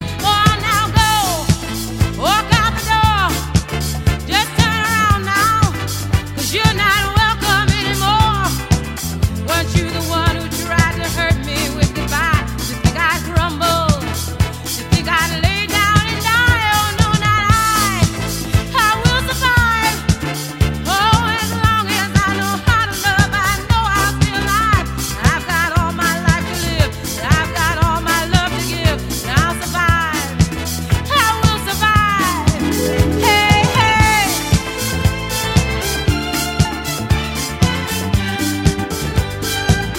диско